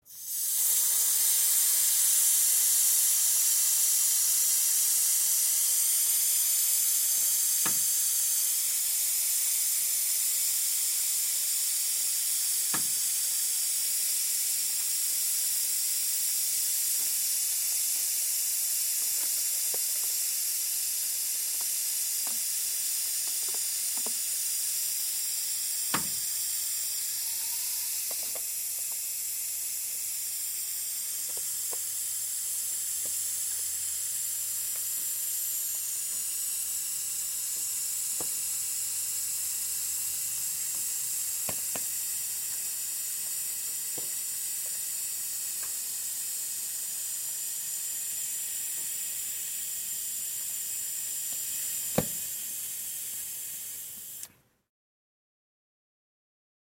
Звуки спускающегося колеса
Стравливаем давление в колесе автомобиля